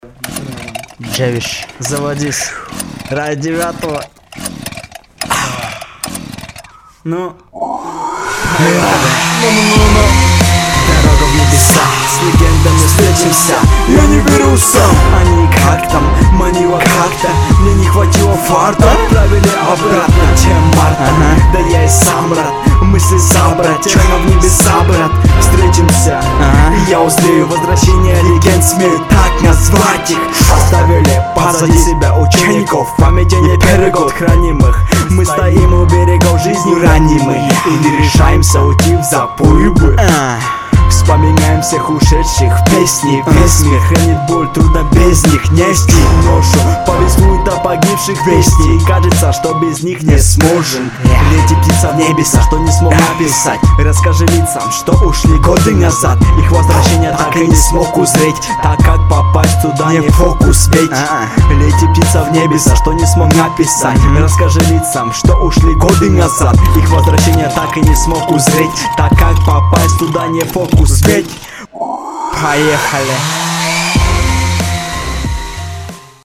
записал трэк на отбор в 9-ый,качество получилось на отьебись,а вот и ссылка:
ну и качество тебе глухой бэки сводил
дааа сведение хромает,там где я писал это сводить только учатся
Качество реально жесть.